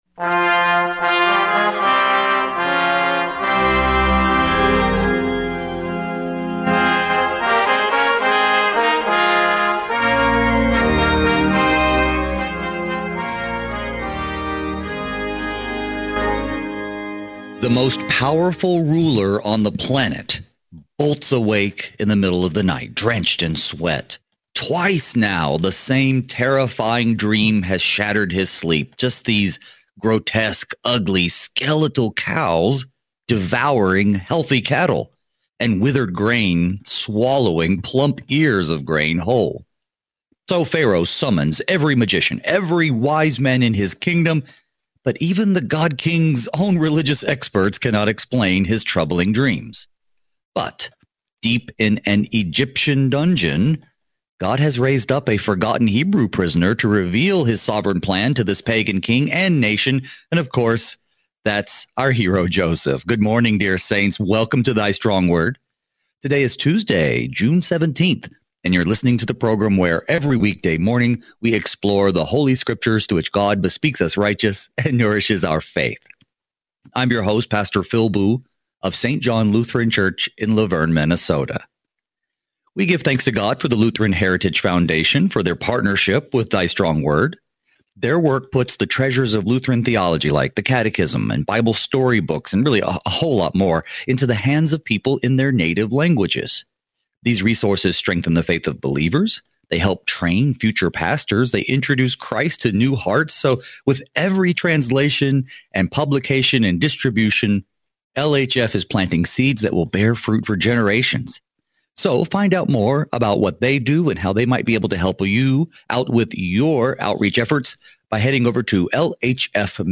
Thy Strong Word reveals the light of our salvation in Christ through study of God’s Word, breaking our darkness with His redeeming light. Each weekday, two pastors fix our eyes on Jesus by considering Holy Scripture, verse by verse, in order to be strengthened in the Word and be equipped to faithfully serve in our daily vocations.
… continue reading 1437 episodes # Thy Stong Word # Bible Study # KFUO Radio # Lutheran # Society # Religion # Christianity # KFUO